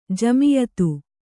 ♪ jamiyatu